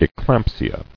[e·clamp·si·a]